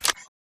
Special-Click-Sound-2.mp3